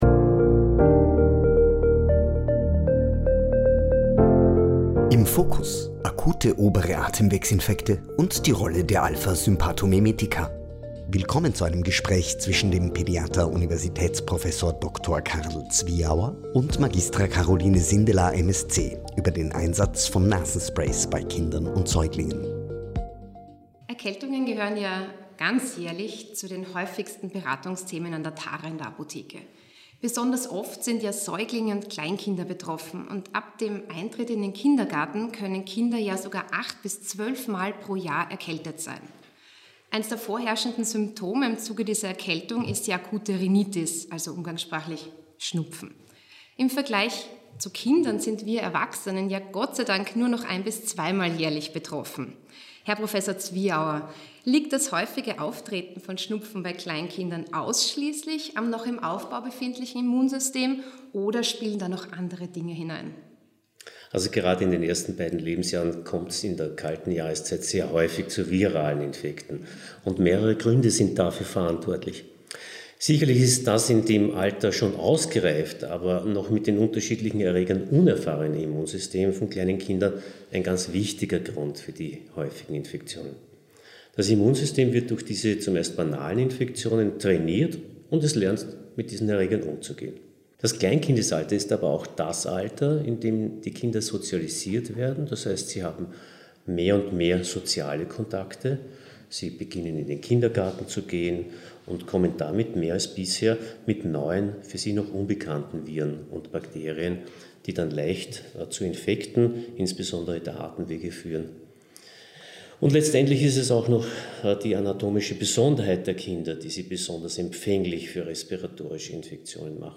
Sie hören ein Gespräch zwischen dem Pädiater